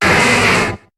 Cri de Lokhlass dans Pokémon HOME.